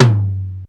Rhythm Machine Sound "TR-707"
lowtom.wav